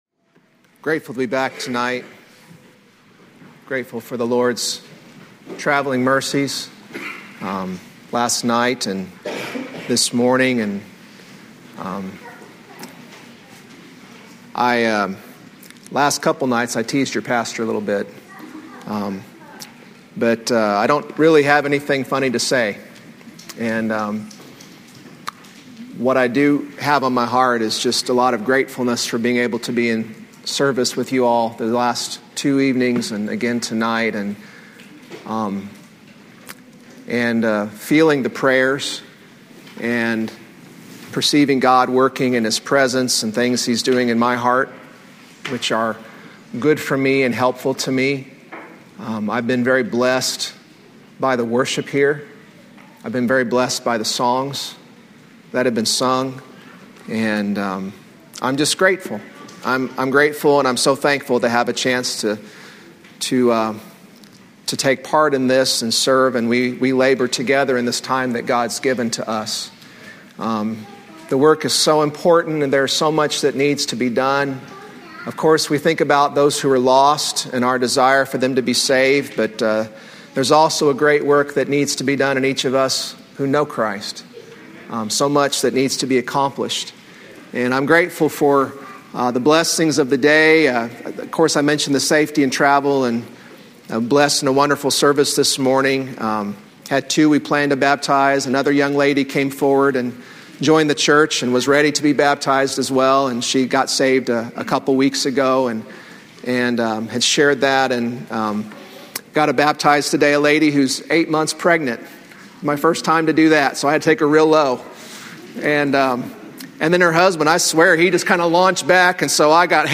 Thursday night sermon from the 2004 session of the Old Union Ministers School.